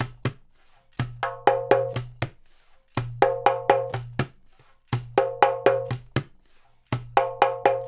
122-PERC1.wav